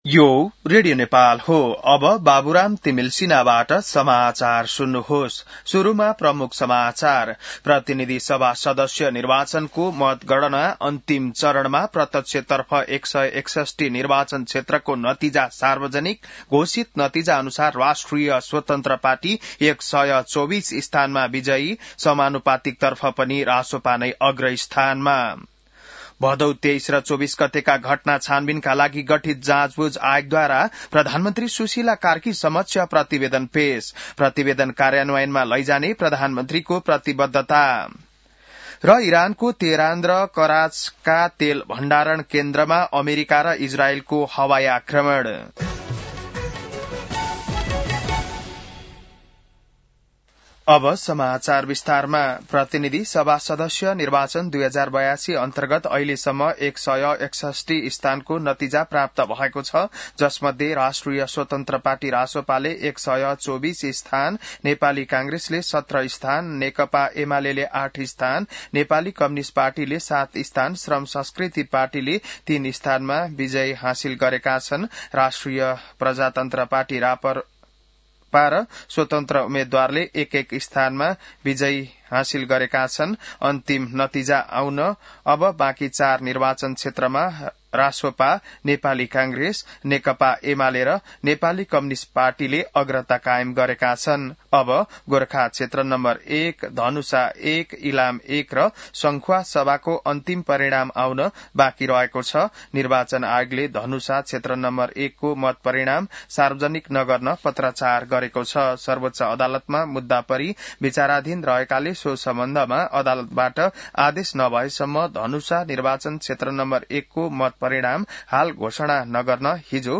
बिहान ९ बजेको नेपाली समाचार : २५ फागुन , २०८२